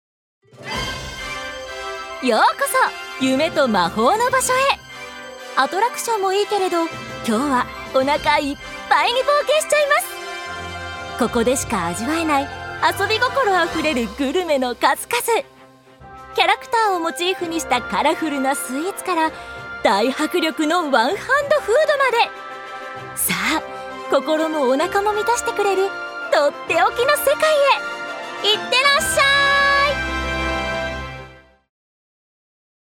女性タレント
ナレーション６